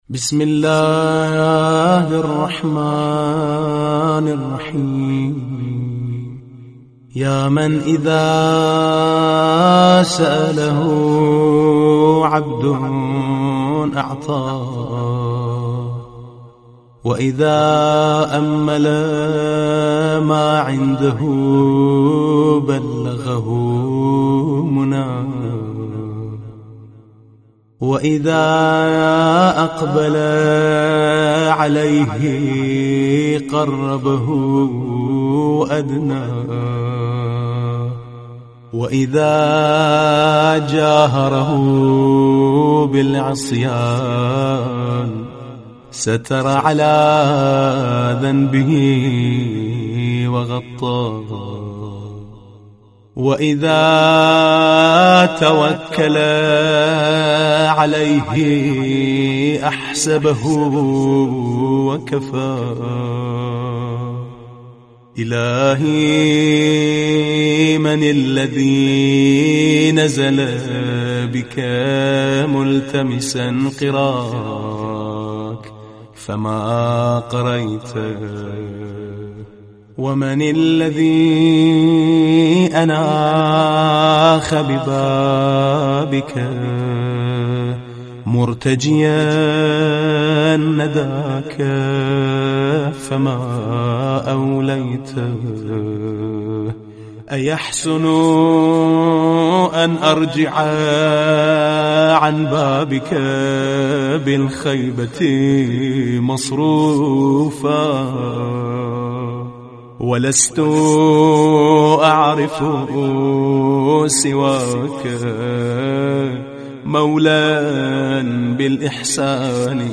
صوت مناجات
MonajatRajin_Arabi.mp3